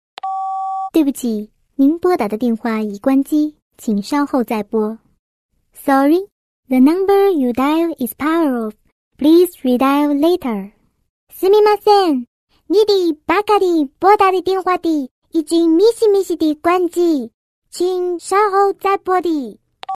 搞笑铃声